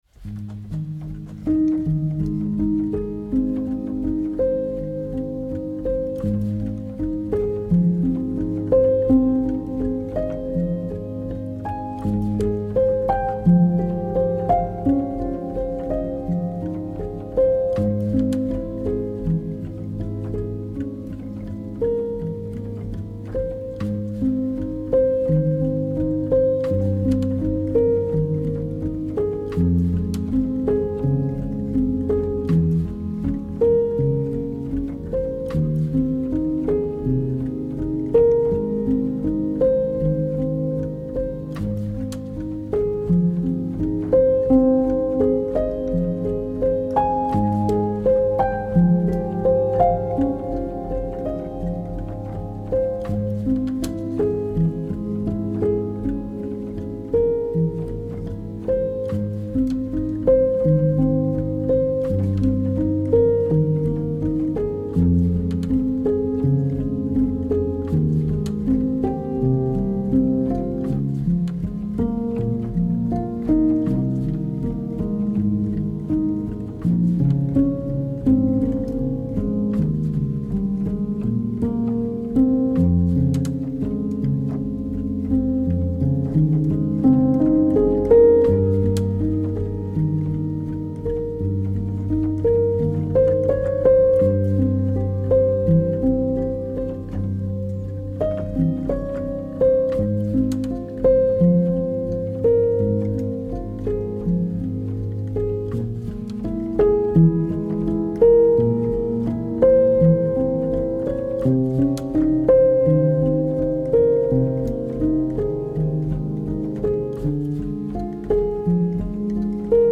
سبک آرامش بخش , پیانو , تخیلی و رویایی , موسیقی بی کلام
موسیقی بی کلام رویایی